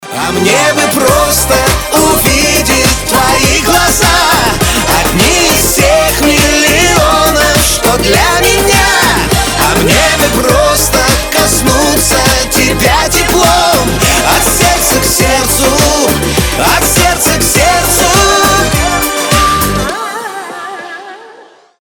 • Качество: 320, Stereo
мужской вокал
громкие
русский шансон
эстрадные
русская эстрада